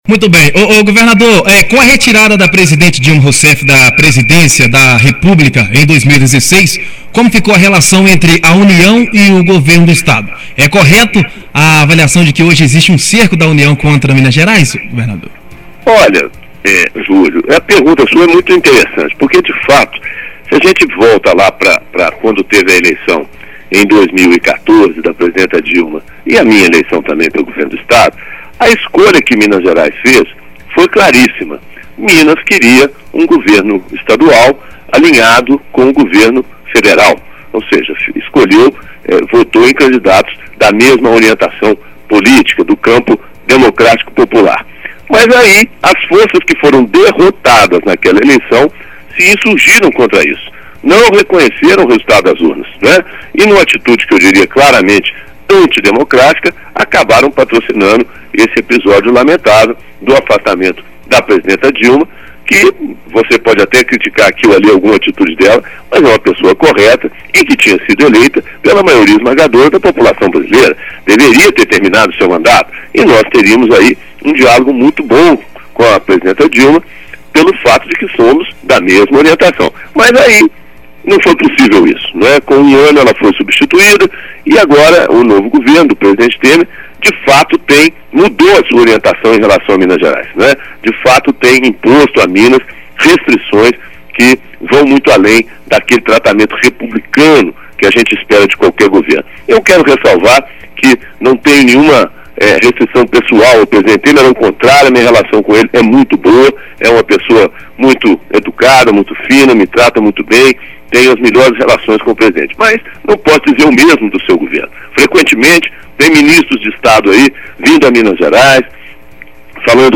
GOVERNADOR DE MG FERNANDO PIMENTEL CONCEDE ENTREVISTA EXCLUSIVA À RÁDIO MONTANHESA